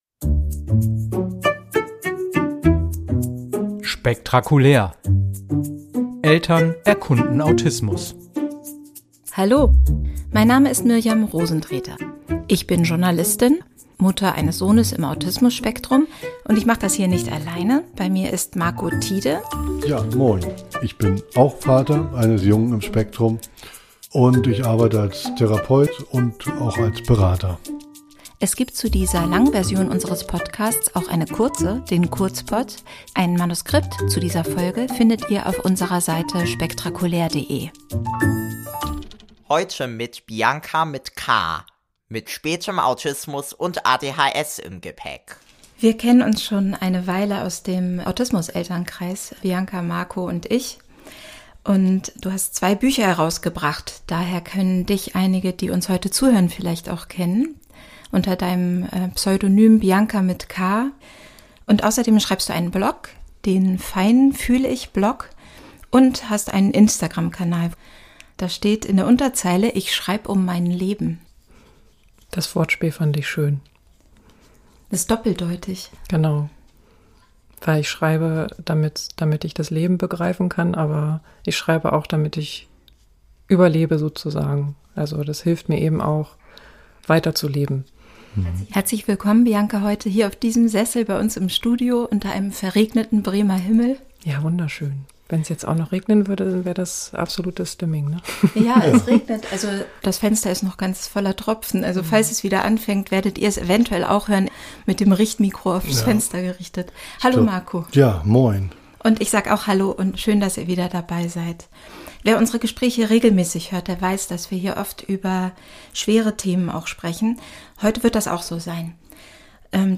Es ist ein Gespräch über Trauer und den Umgang mit Ausnahmesituationen. Und doch steckt es voller Zuversicht und sogar Humor.